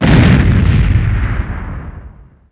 explo.wav